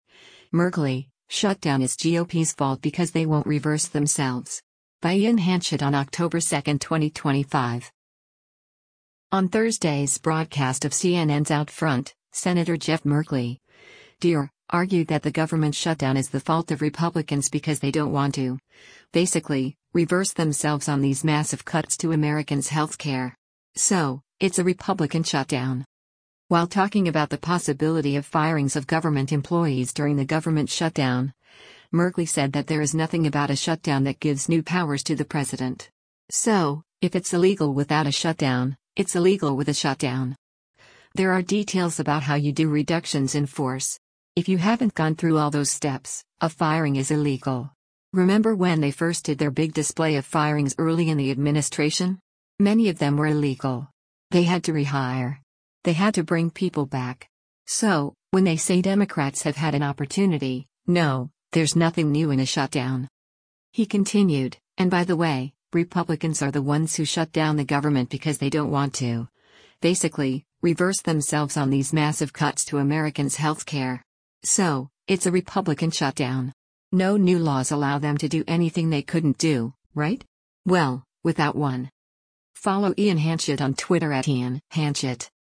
On Thursday’s broadcast of CNN’s “OutFront,” Sen. Jeff Merkley (D-OR) argued that the government shutdown is the fault of Republicans “because they don’t want to, basically, reverse themselves on these massive cuts to Americans’ health care. So, it’s a Republican shutdown.”